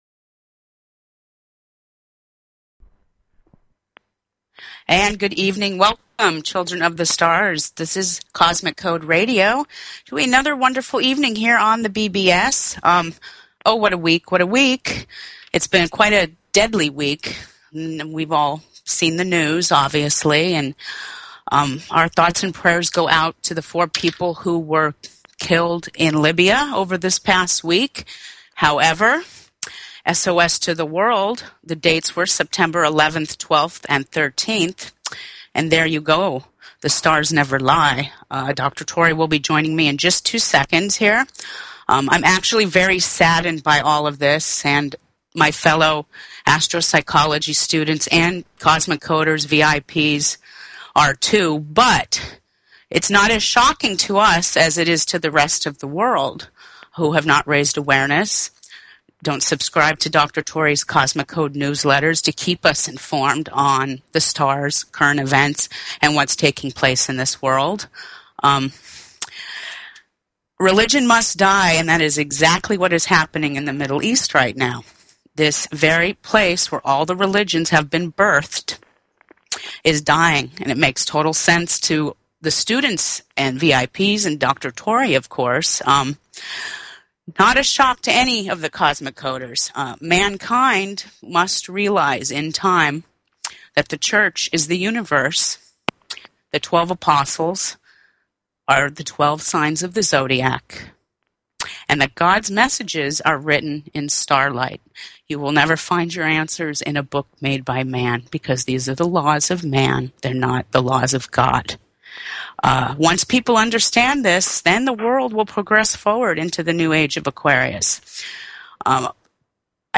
Talk Show Episode, Audio Podcast, Cosmic_Radio and Courtesy of BBS Radio on , show guests , about , categorized as